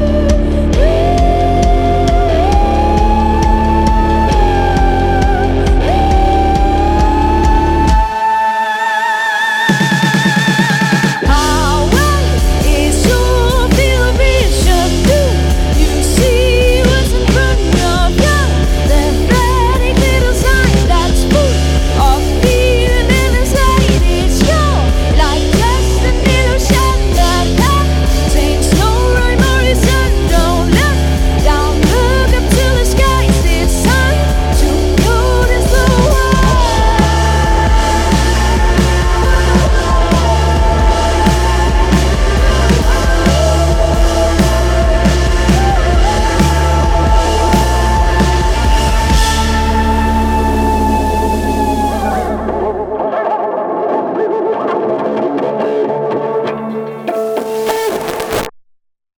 • Indie
• Punk
• Rock
dansk punkband.